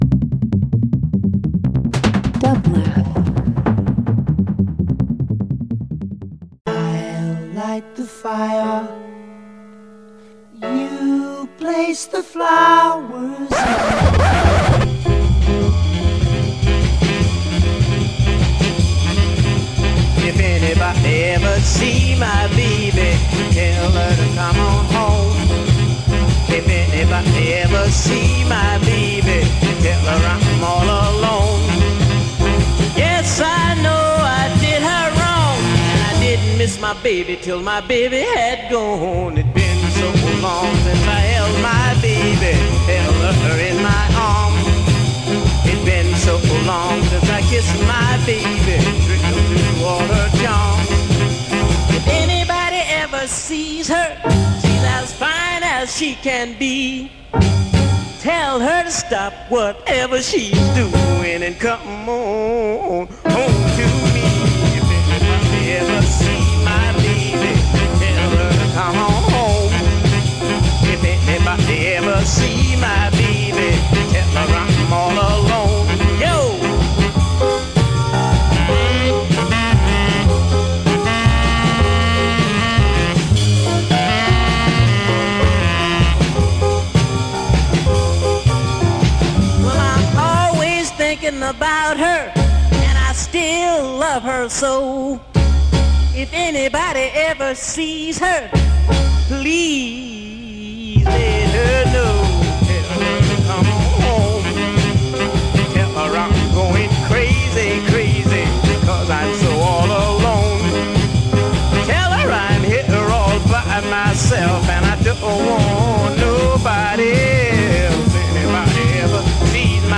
Experimental International Post Rock Punk